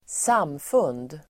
Uttal: [²s'am:fun:d]